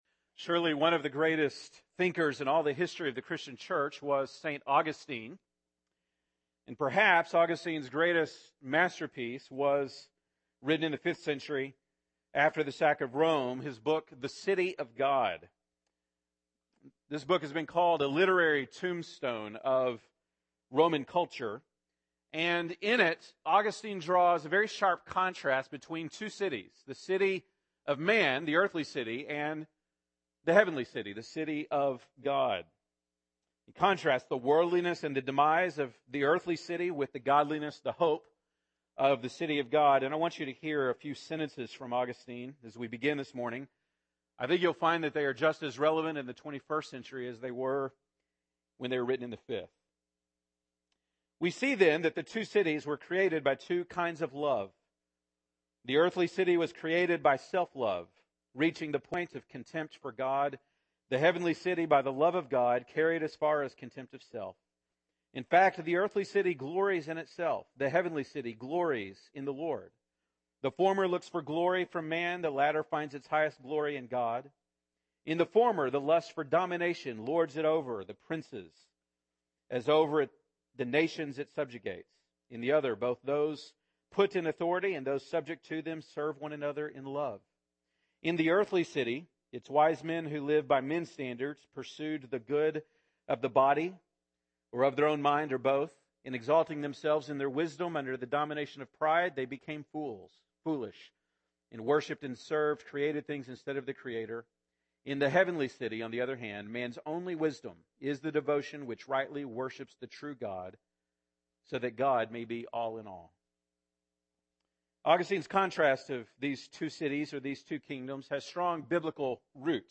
September 13, 2015 (Sunday Morning)